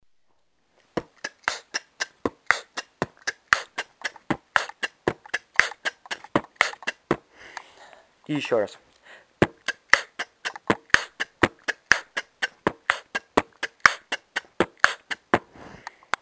Оцените Хэнд Клэп
Записывал на камеру, потом конвертировал, так что может немного хрипеть, но в целом звук слышен!
b t kch tt b kch t
у тебя похоже микрофон какой-то не хороший(
хендклеп...не знаю даже...прости, но как то он не так звучит...может вживую конечно все иначе, но, судя по записи не оч...